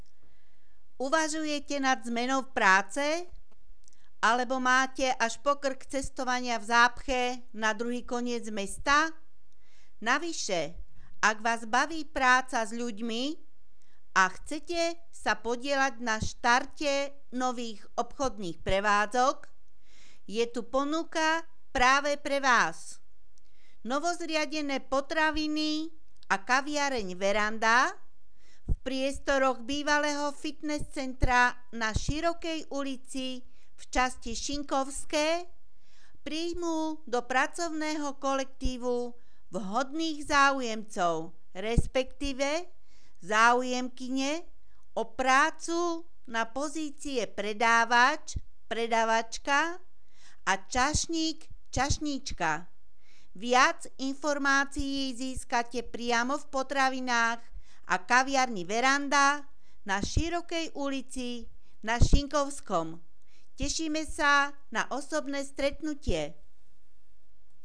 Hlásenie miestneho rozhlasu 12.10.2016 (Komerčný oznam: Potraviny a kaviareň Veranda)